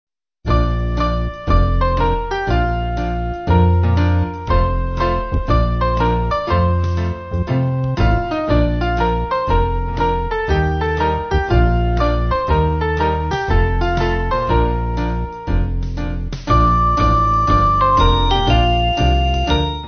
Kid`s club music
2/Bb